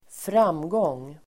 Uttal: [²fr'am:gång:]